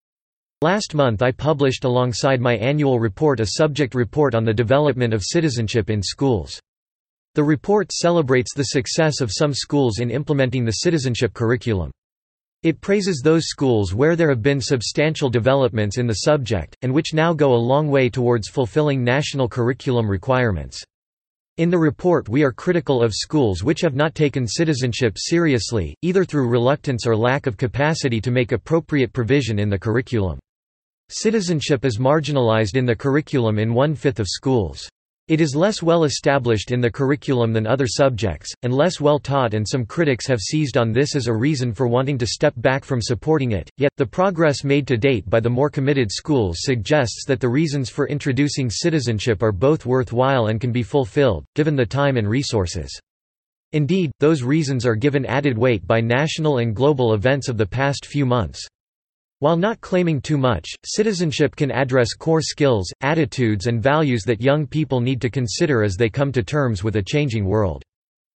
You will hear a short lecture.